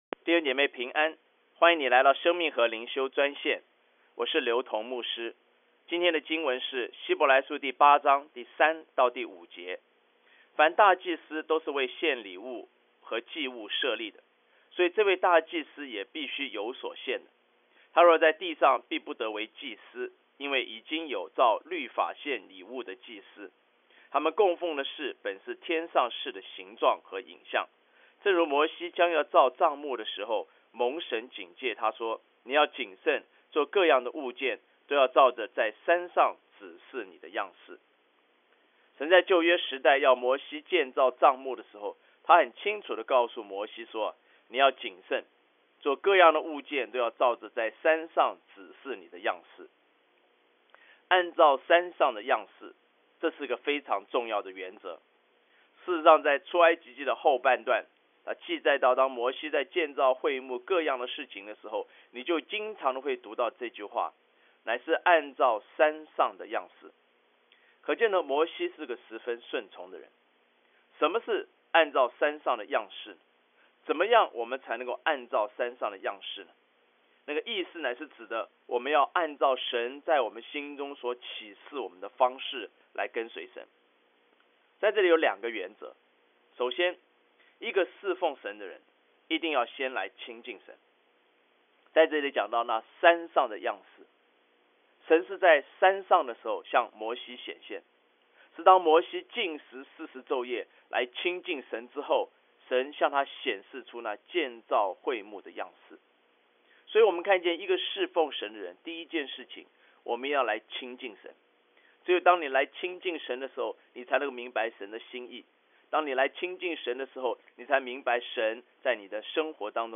以生活化的口吻带领信徒逐章逐节读经
每日灵修